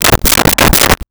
Knocking On Door With Knuckles 4 Times
Knocking on Door with Knuckles 4 times.wav